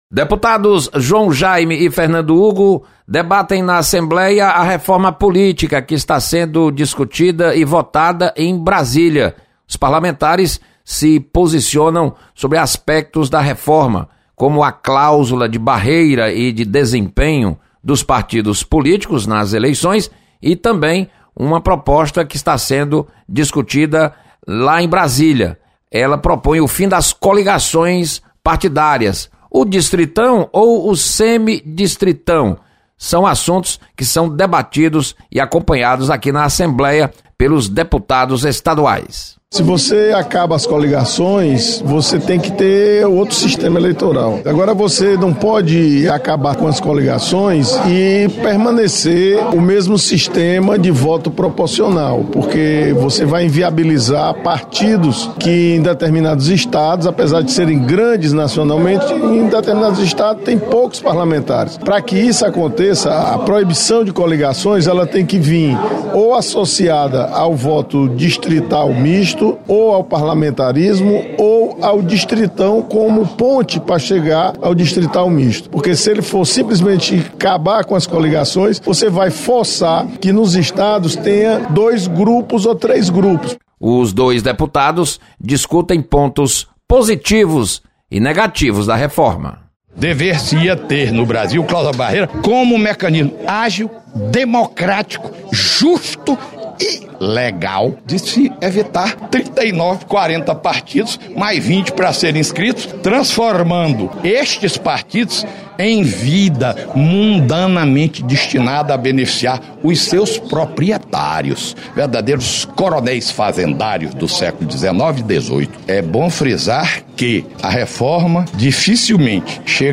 Deputados comentam sobre PEC da reforma política.